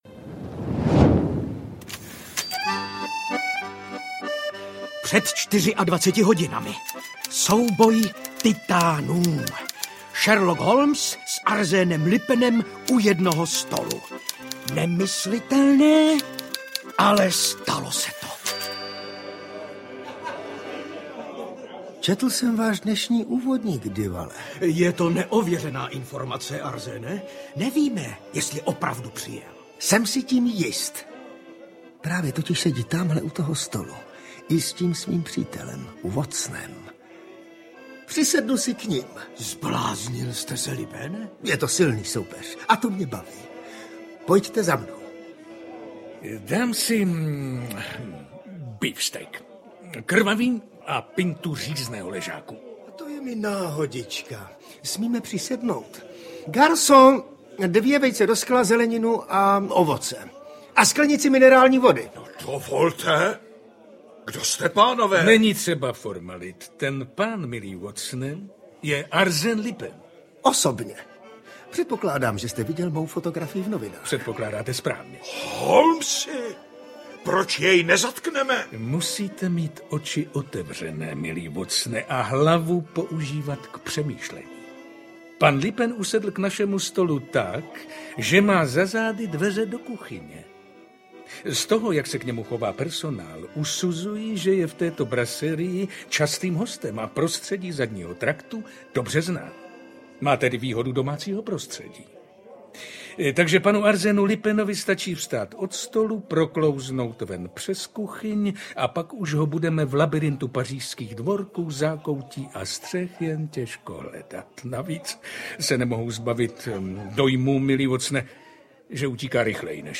Audiokniha Arsène Lupin kontra Sherlock Holmes - Blondýnka a modrý diamant - obsahuje dramatizaci příběhu podle Maurice Leblanca, kde se setkají Arsène Lupin a Sherlock Holmes
Ukázka z knihy